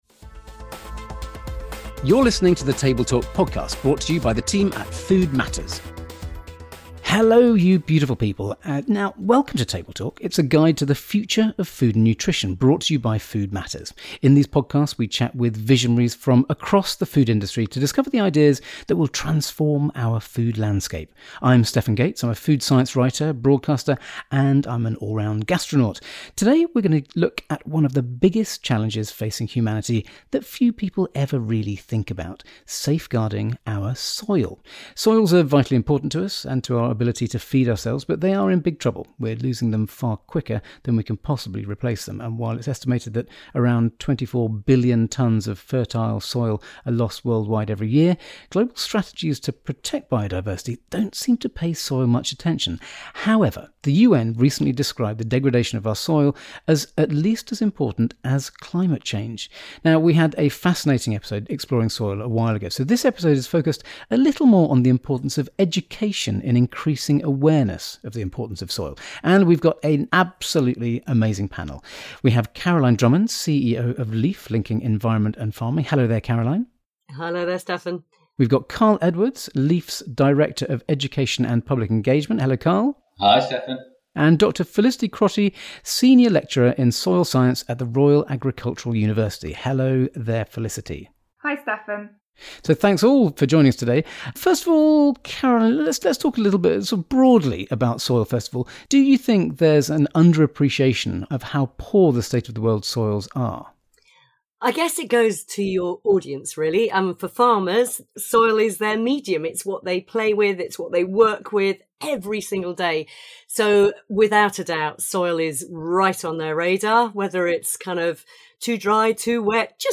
We'll discuss the scale of the challenge we face, what is happening now to raise awareness of the issues we're facing, and what we can do to fix our agriculture system. Join us for a fascinating and lively debate on Table Talk.